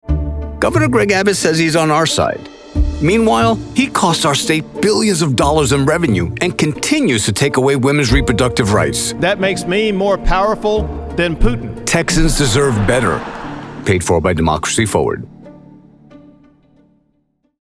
Political Voice Over for Democratic Campaigns
Deep, authoritative, and credible — the voice your audience trusts before the message registers.
Custom-built isolation booth, Sennheiser MKH 416, Audient id14mkII interface, Studio One Pro with iZotope RX. Broadcast quality on every take.